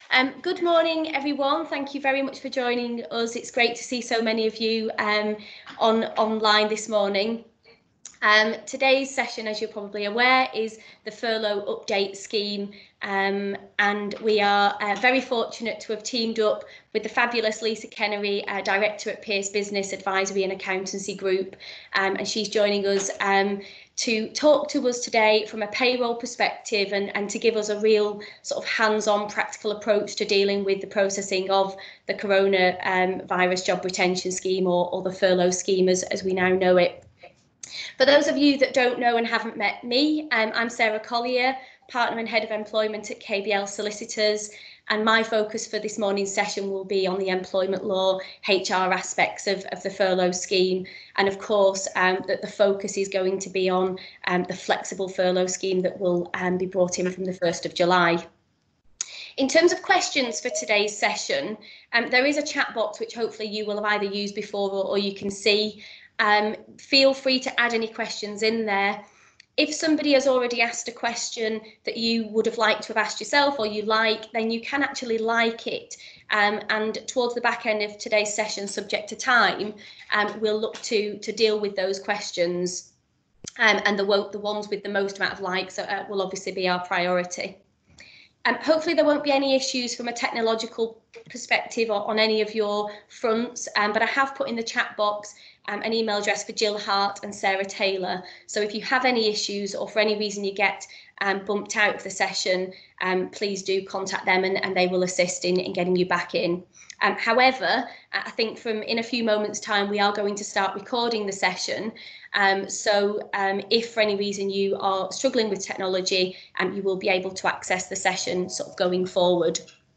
Furlough-Update-webinar.mp3